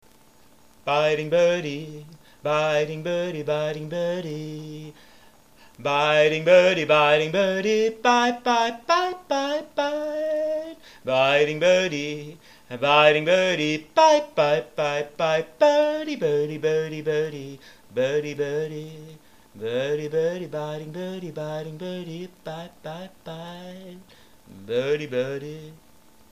And they respond.
[n.b. Phlegm in my throat made singing difficult.]
[n.b. The Budgies calls had to be increased in volume,
thus increasing background computor hum.]
My Singing To Them - 4th November 2007 [2].